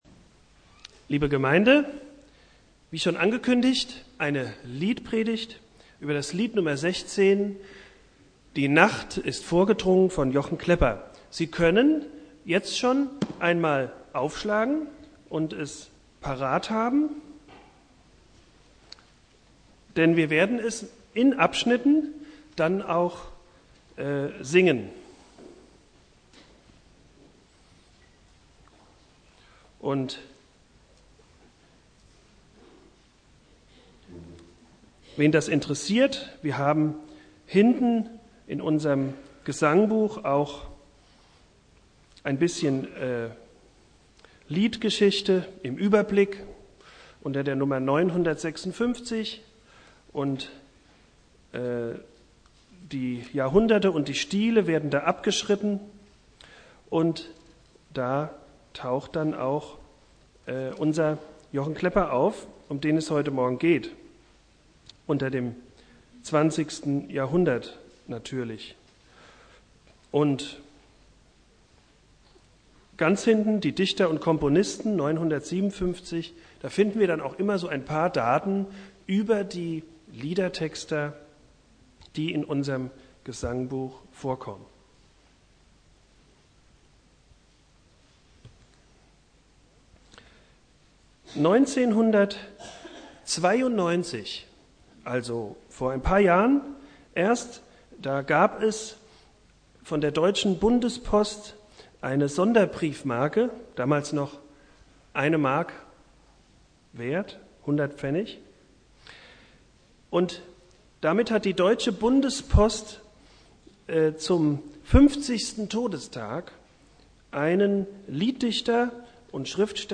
Predigt
Thema: "Jochen Klepper" Predigtreihe: Themenpredigten Dauer